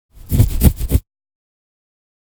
scratch sounds.
scratch.wav